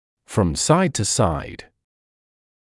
[frɔm saɪd tuːsaɪd][фром сайд туː сайд]из стороны в сторону